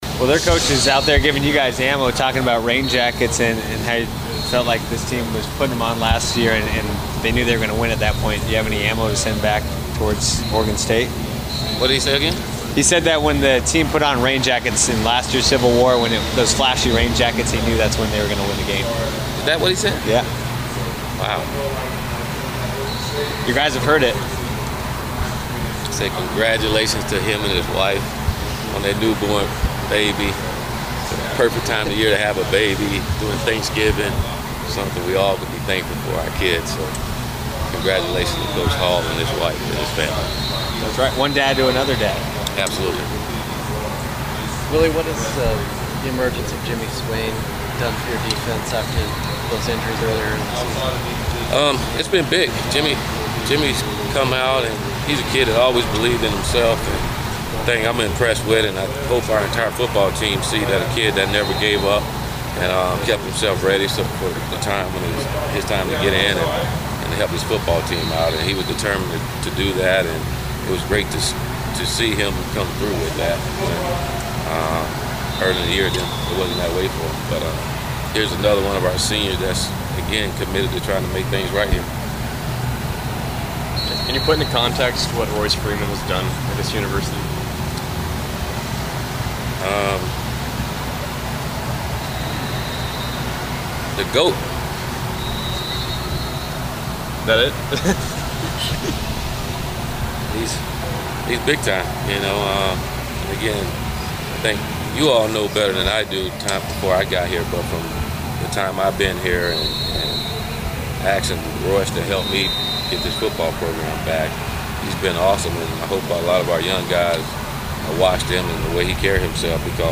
Willie Taggart Media Session 11-22-17